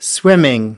17 swimming (n) /ˈswɪmɪŋ/ Việc bơi lội